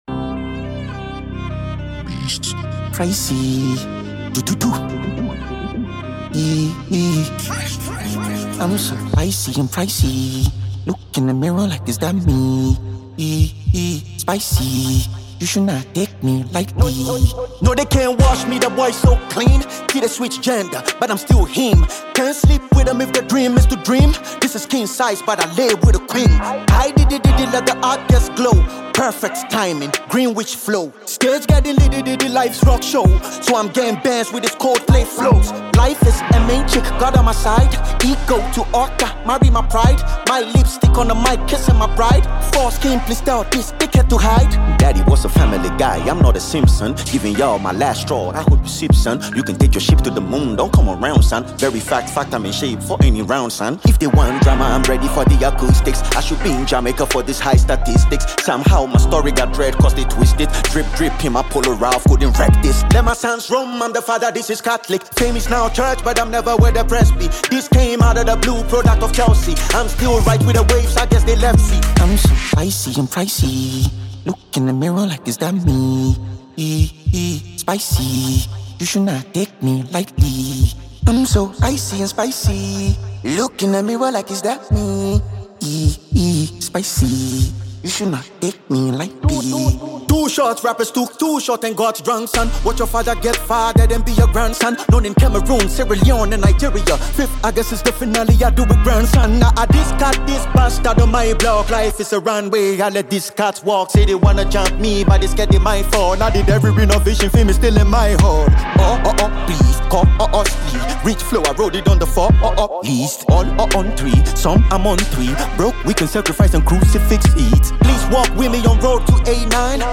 New from Ghanaian rapper